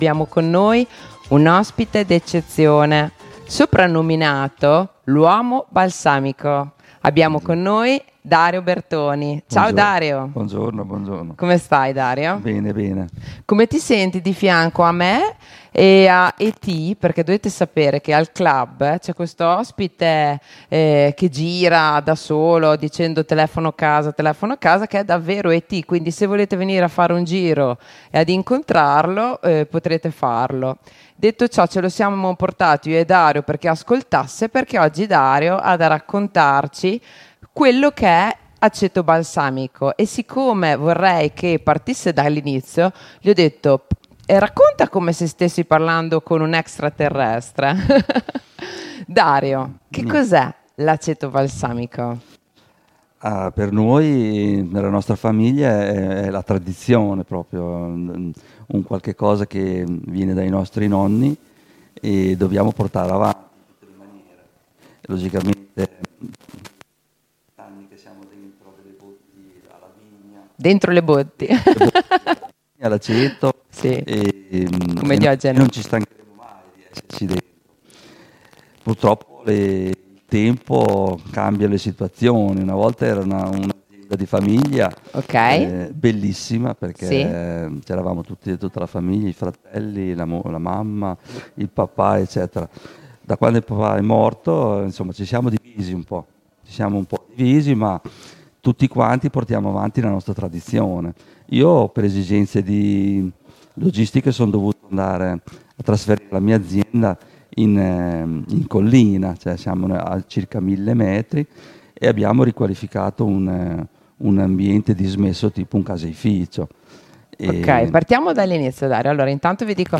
L’uomo balsamico – Le interviste di Linea Radio al Clhub di viale XX Settembre a Sassuolo